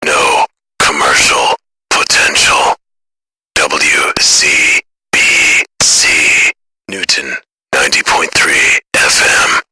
NCP sinister Legal (dry voice)
NCP Drones.mp3